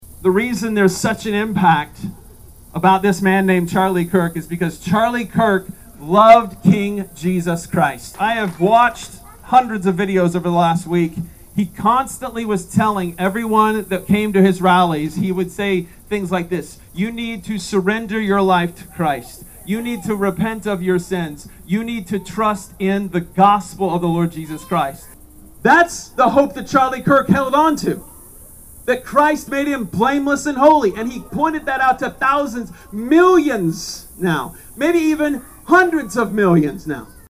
An estimated 300 people gathered outside of Bartlesville City Hall Tuesday night to remember the life and legacy of Charlie Kirk.
The vigil included hymns, prayer and messages from evangelical leaders from across the area.